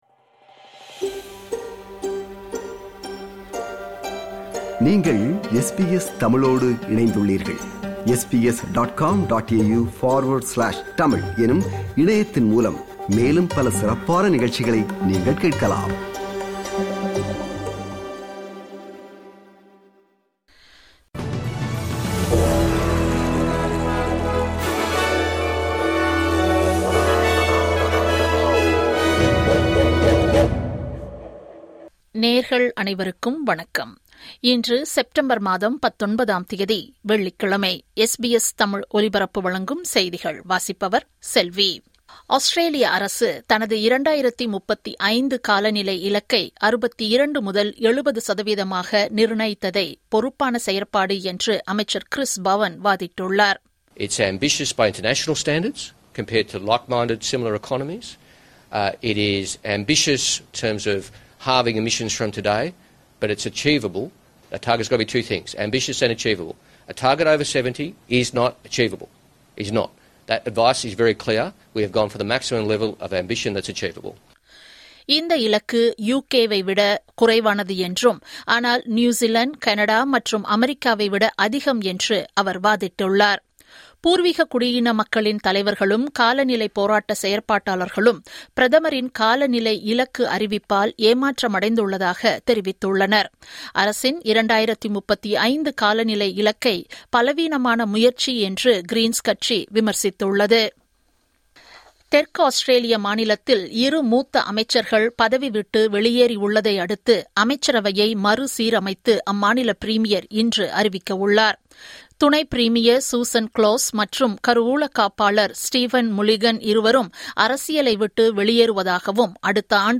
இன்றைய செய்திகள்: 19 செப்டம்பர் 2025 வெள்ளிக்கிழமை
SBS தமிழ் ஒலிபரப்பின் இன்றைய (வெள்ளிக்கிழமை 19/09/2025) செய்திகள்.